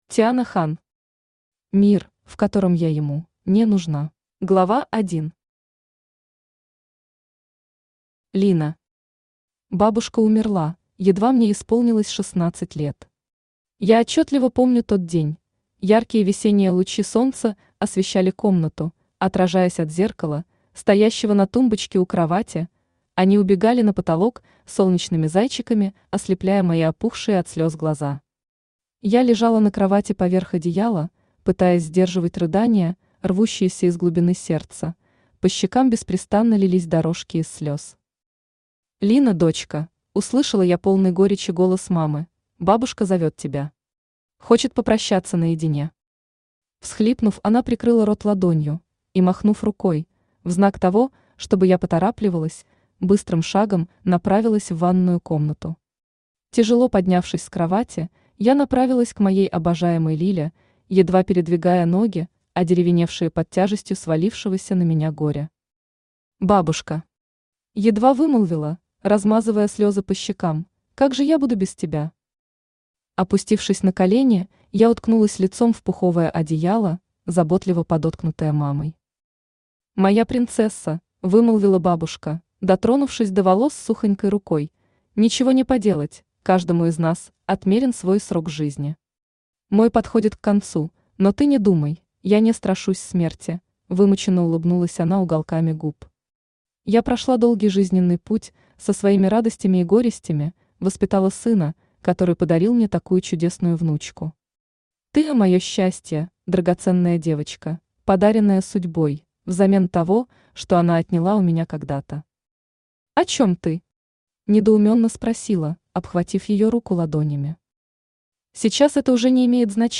Аудиокнига Мир, в котором я ему (не) нужна | Библиотека аудиокниг
Aудиокнига Мир, в котором я ему (не) нужна Автор Тиана Хан Читает аудиокнигу Авточтец ЛитРес.